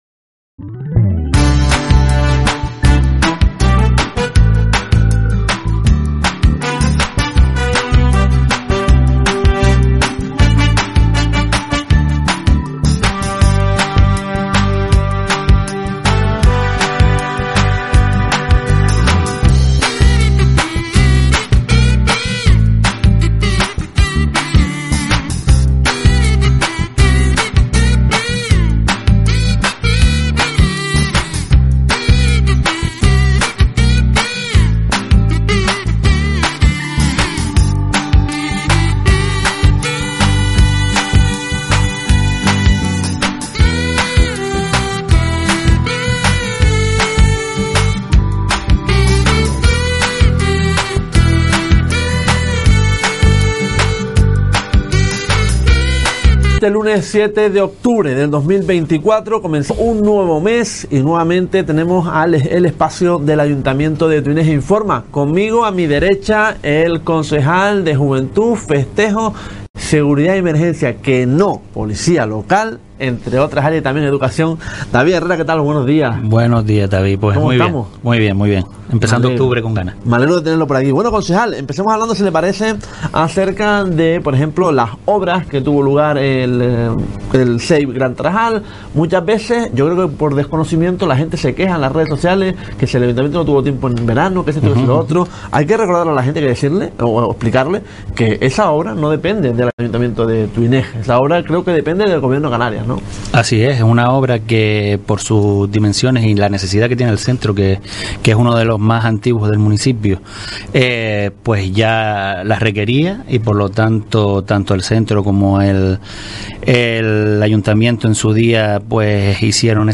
El lunes 07 de octubre nos visitó el concejal de Juventud y Festejos, entre otras áreas ,del Ayuntamiento de Tuineje, David Herrera. Ya puedes escuchar la entrevista online o descargar el audio.